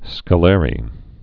(skə-lârē, -lärē)